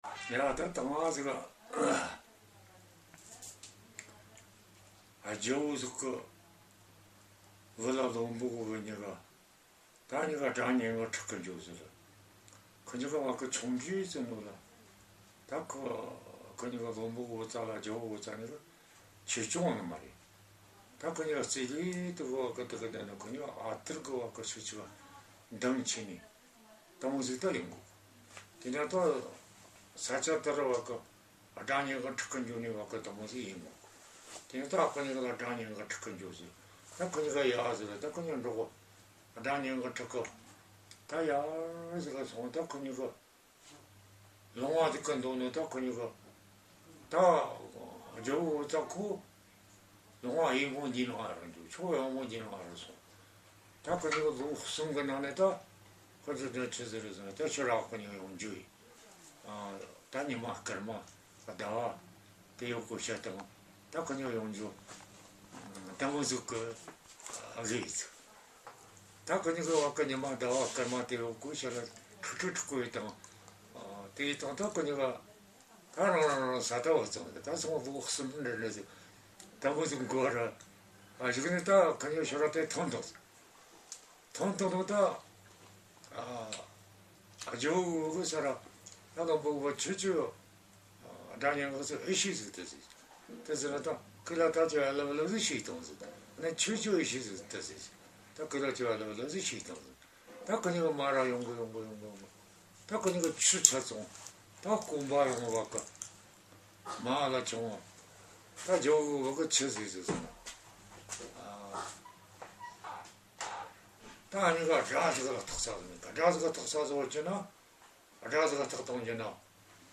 Keywords: Tibet; Folk tales;